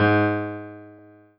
piano-ff-24.wav